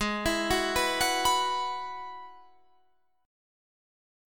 Abm7#5 chord